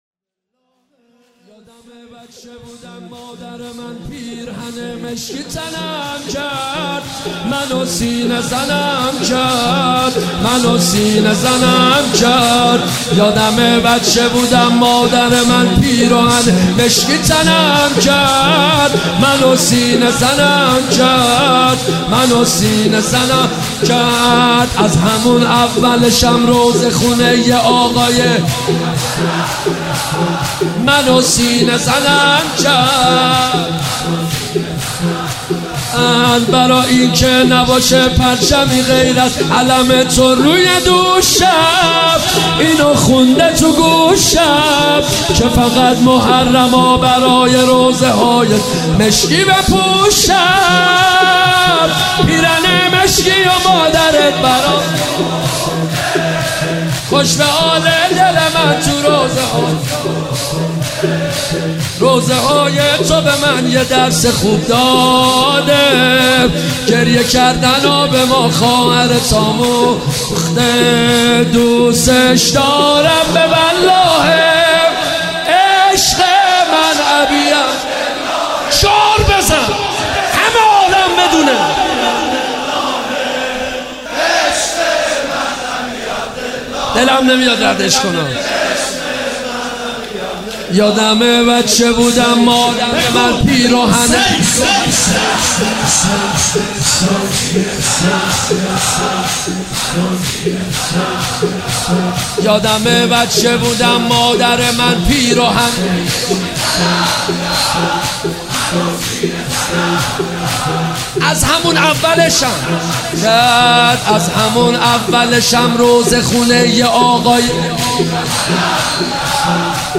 مداحی شروع محرم